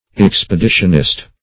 Expeditionist \Ex`pe*di"tion*ist\, n. One who goes upon an expedition.